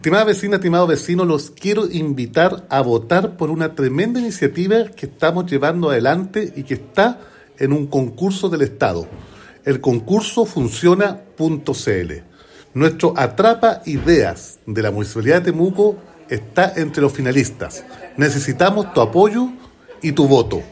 El alcalde Roberto Neira hizo un llamado a los vecinos de Temuco para apoyar esta iniciativa, “invitamos a toda la ciudadanía a votar por ‘Atrapa Ideas’ en el Concurso Funciona. Estamos entre los finalistas, pero necesitamos su apoyo para ganar nuevamente y seguir fortaleciendo la innovación pública desde Temuco”.